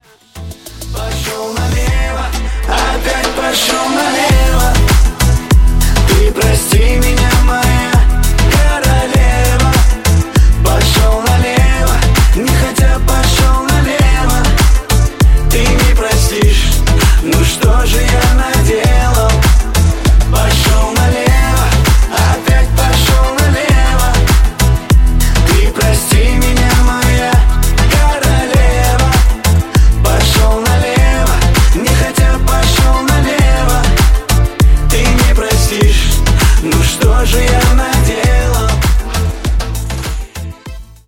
• Качество: 128, Stereo
поп
русский шансон